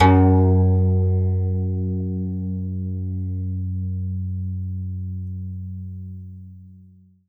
52-str02-zeng-f#1.aif